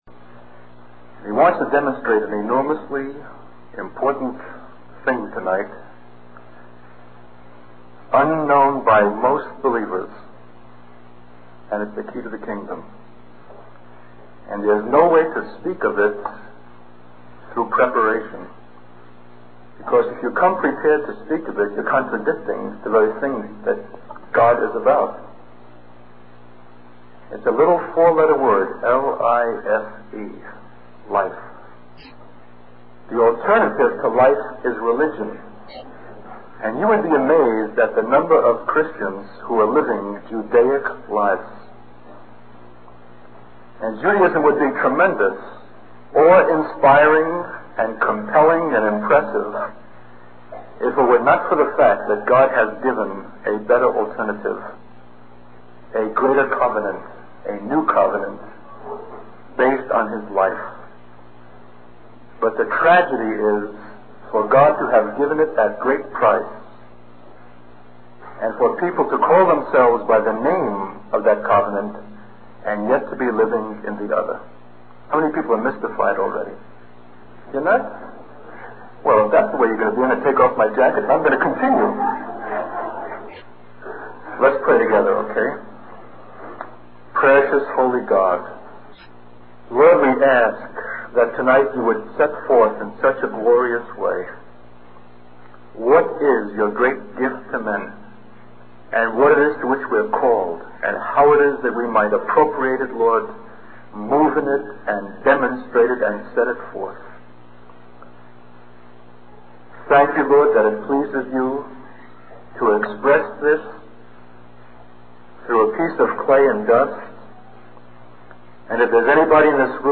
In this sermon, the preacher emphasizes the importance of understanding the concept of life versus religion. He highlights that many Christians are living religious lives instead of embracing the new covenant based on God's life.